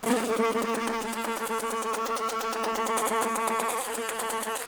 fly3.wav